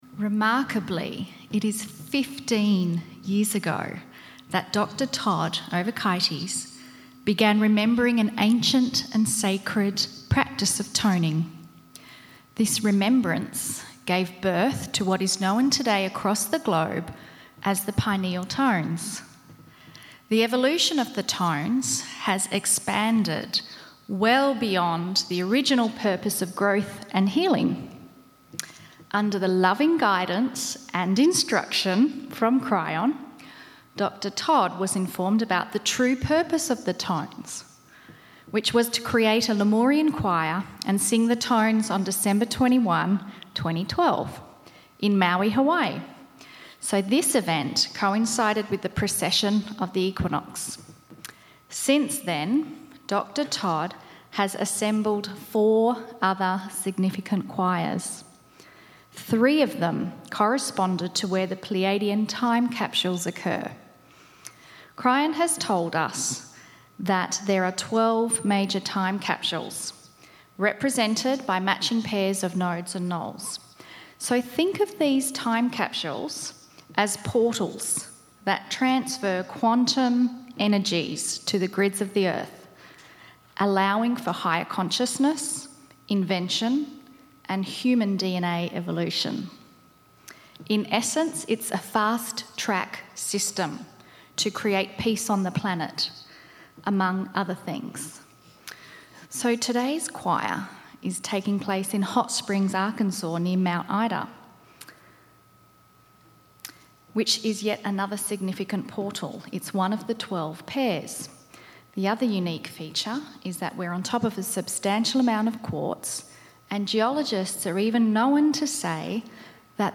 Spotkanie Kryształowego Chóru Pineal Tone
Crystal Choir Channelllings-2.mp3